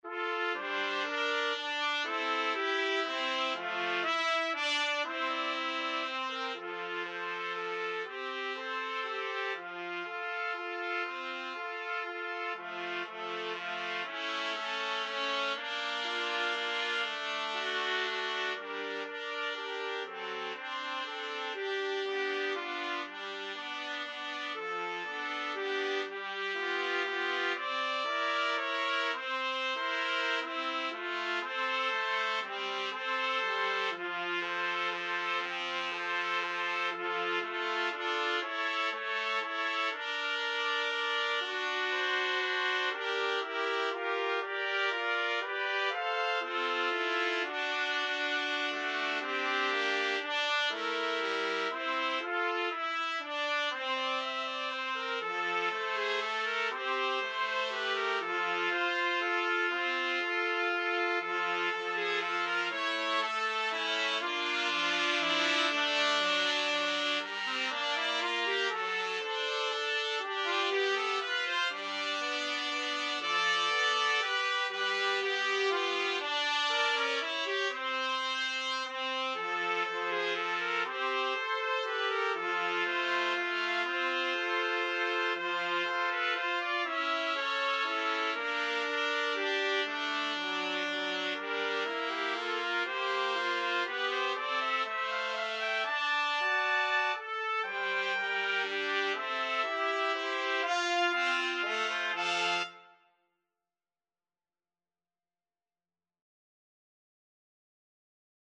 Trumpet 1Trumpet 2Trumpet 3
3/4 (View more 3/4 Music)
= 120 Tempo di Valse = c. 120
Jazz (View more Jazz Trumpet Trio Music)